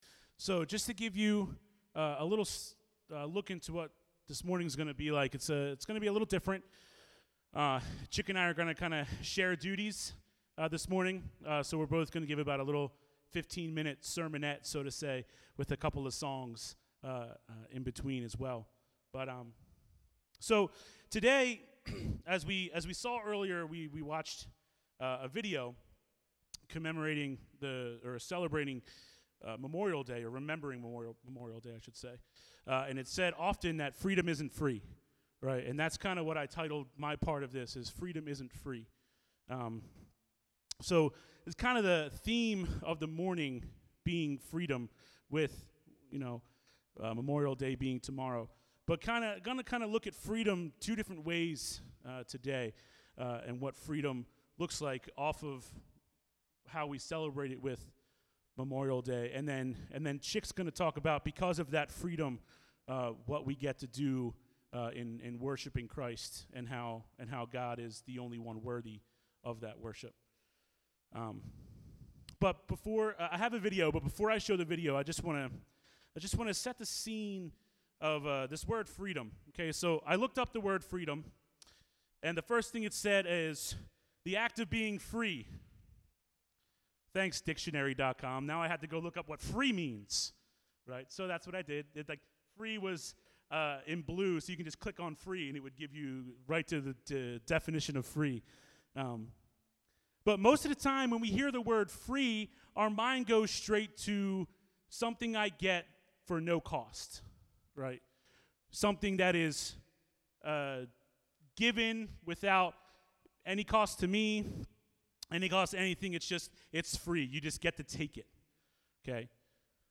Memorial Day message 2016